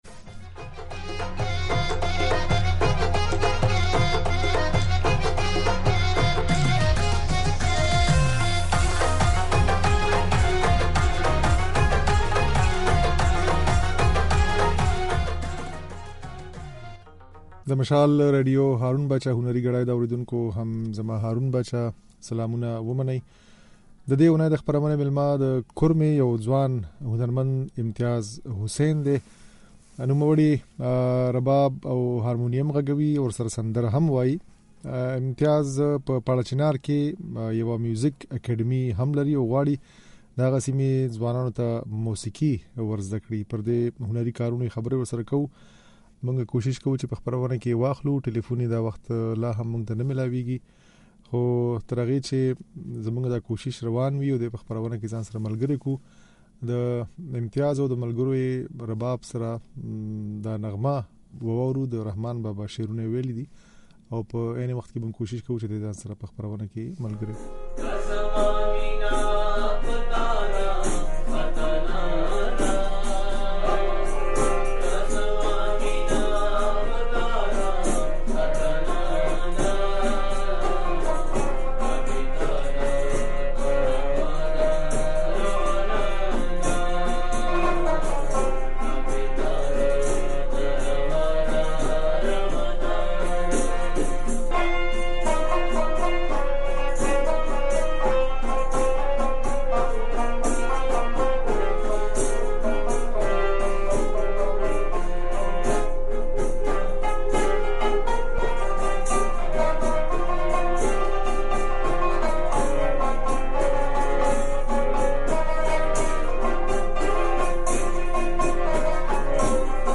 خبرې او غږولې ځينې سندرې يې په خپرونه کې اورېدای شئ